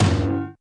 bonk.ogg